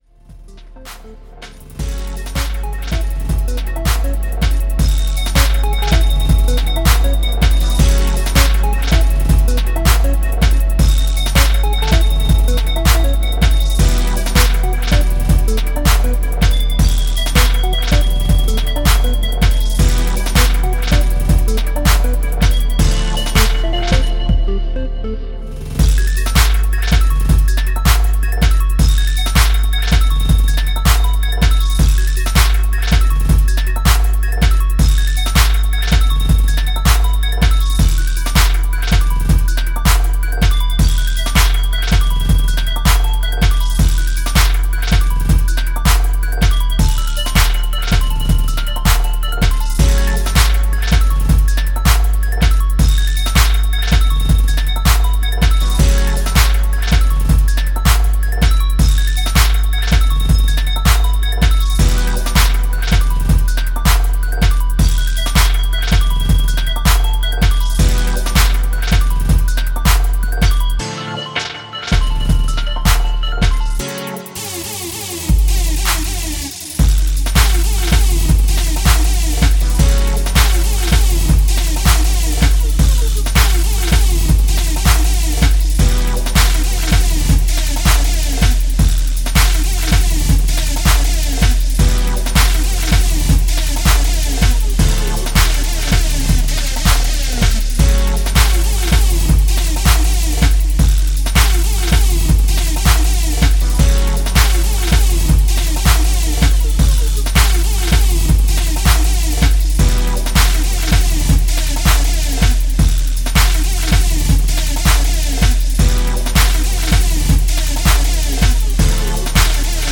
classic rave sounds